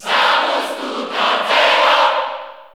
Category: Crowd cheers (SSBU) You cannot overwrite this file.
Zero_Suit_Samus_Cheer_Italian_SSB4_SSBU.ogg